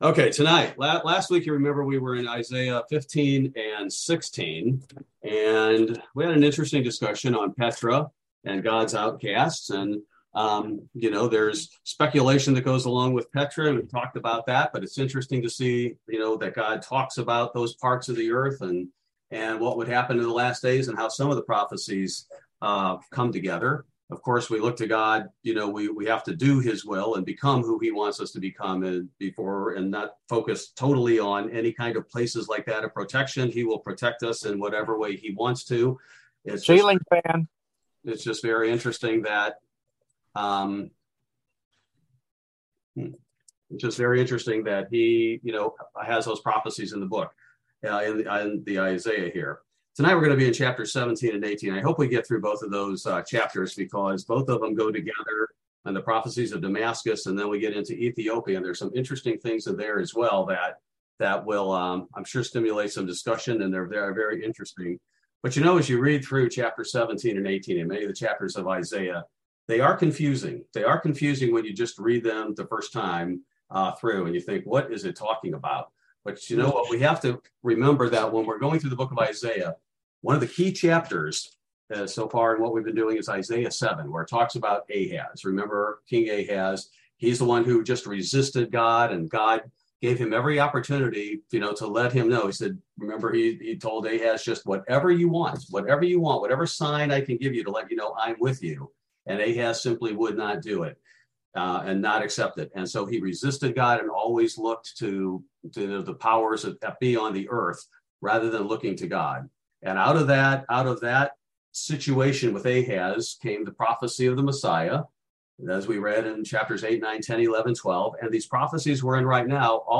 Bible Study: November 30, 2022